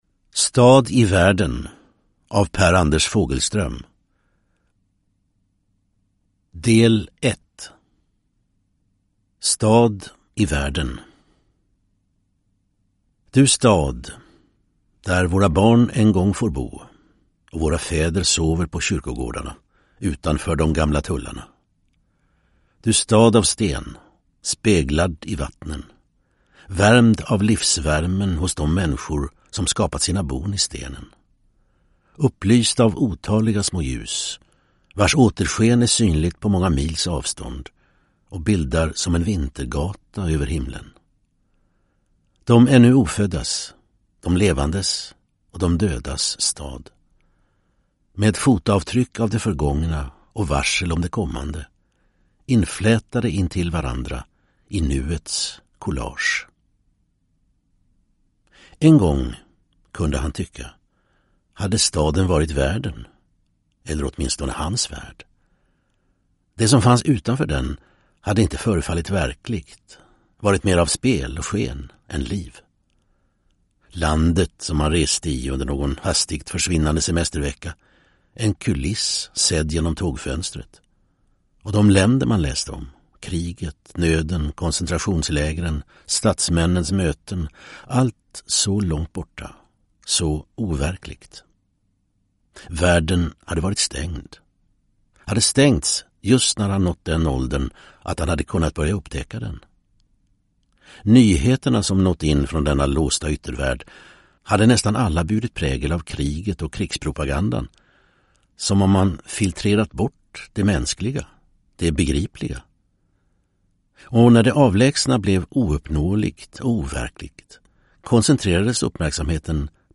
Stad i världen (ljudbok) av Per Anders Fogelström